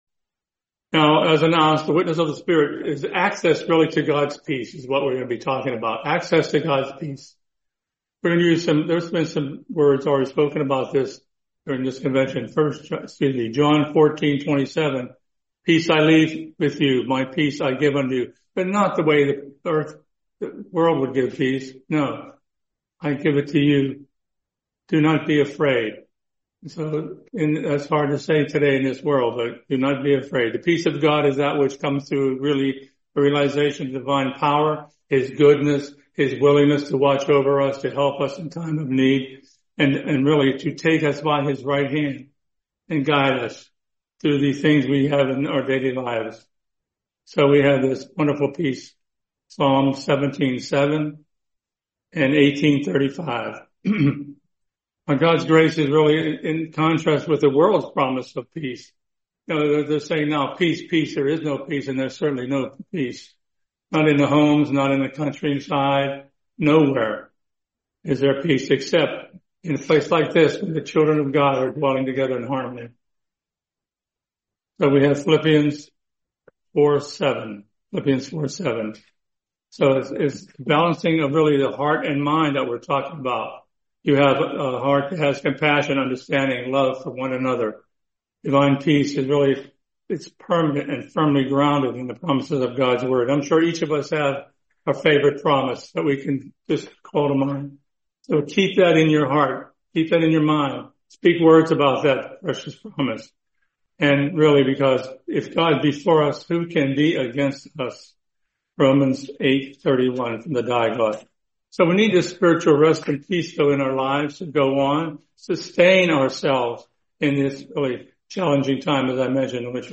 Series: 2025 West Newton Convention